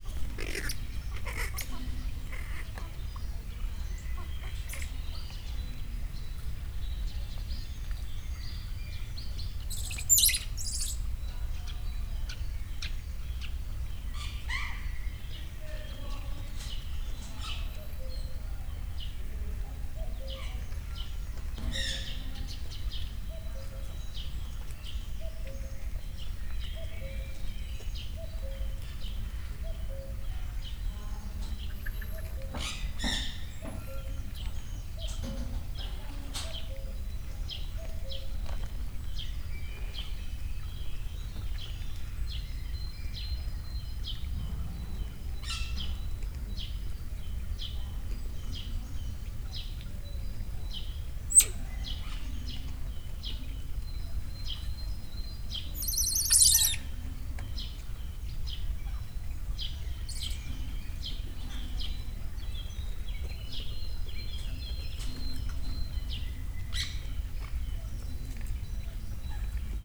Directory Listing of /_MP3/allathangok/miskolcizoo2009_standardt/mokusmajom/ home > allathangok > miskolcizoo2009_standardt > mokusmajom File Size Last Modified ..
kakukkis01.09.wav